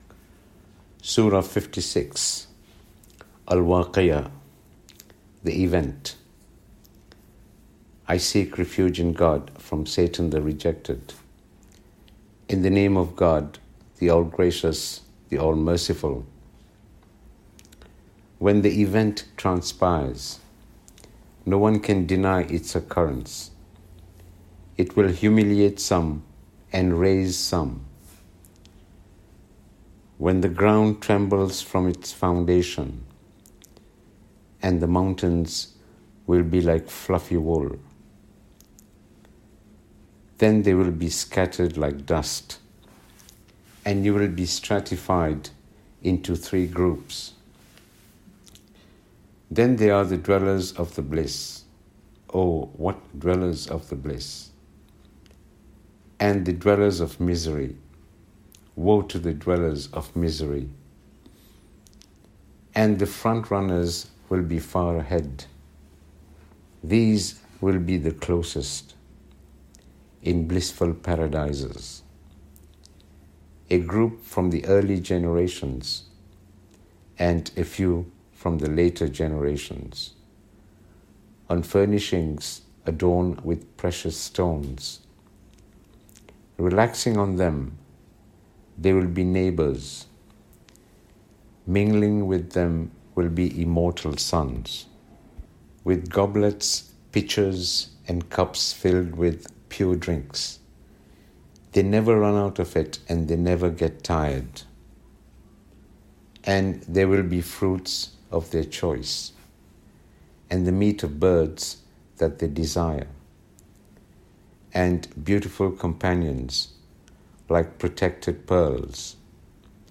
Quran English Reading